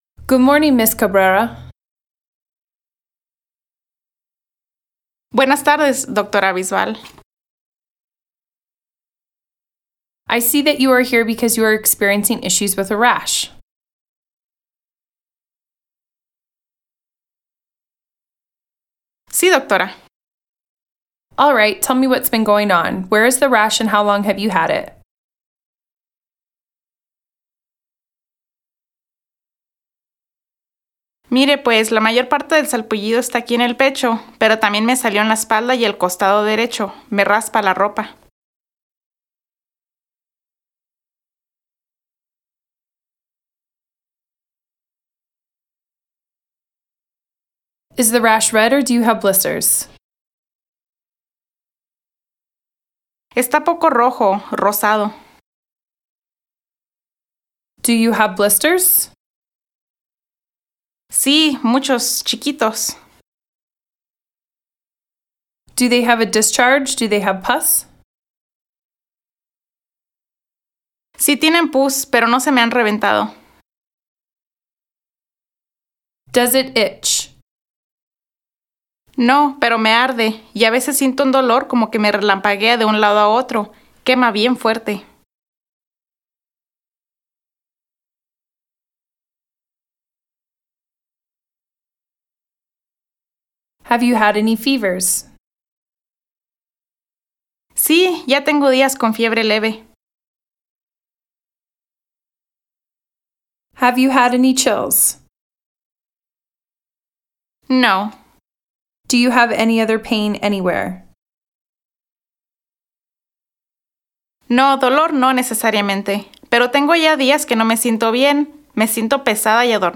VCI-Practice-Dialogue-05-Shingles-EN-SP.mp3